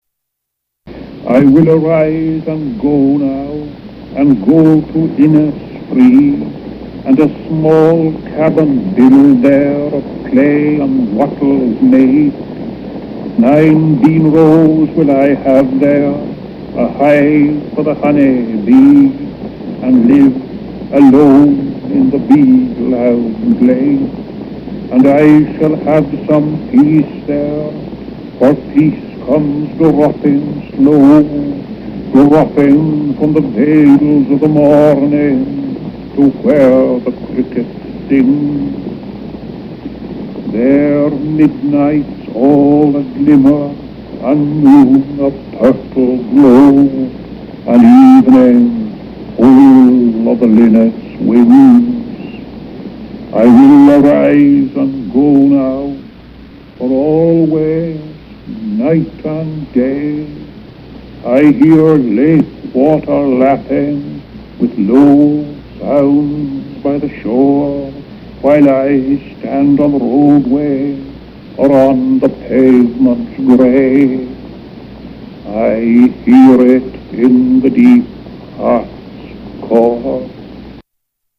Poetry Recitation
This is a three and a half minute BBC radio broadcast of William Butler Yeats's own voice in 1932 reciting two of his poems, one of which is the most famous of his early poetry, "The Lake Isle of Innisfree" (1888).
"I’m going to read my poems with great emphasis upon their rhythm," he says, "...and that is why I will not read them as if they were prose."